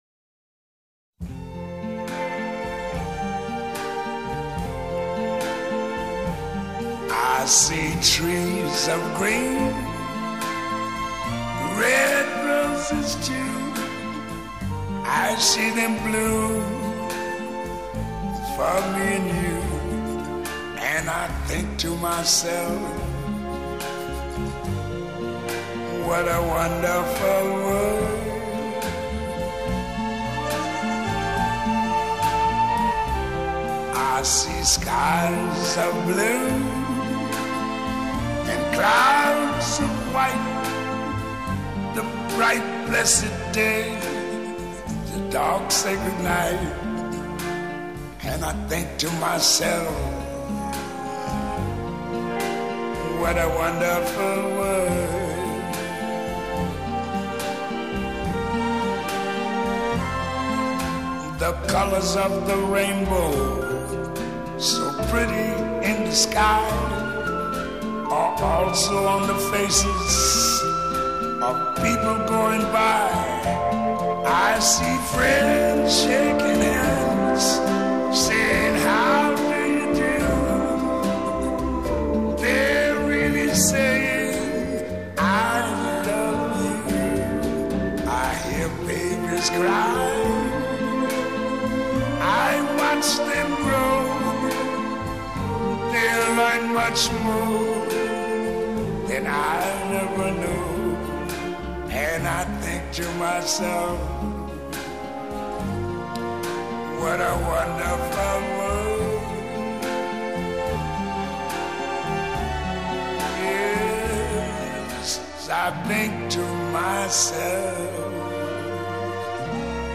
轻柔的爵士钢琴和着淡淡的爵士萨克斯风，弥漫于午夜的微风中，几许悸动，几许情愁。
本辑所选曲目尽显轻柔爵士本色实为柔情爵士之经典大碟。